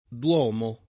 duomo [
dU0mo] s. m. («chiesa») — pop. o poet. domo [d0mo]: Migliore del Clero Che bazzica in Domo [mil’l’1re del kl$ro ke bb#ZZika in d0mo] (Giusti); O Montagne, terribili dòmi abitati da Dio [